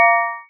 Blacksmith's Hammer
blacksmithhammer_0.mp3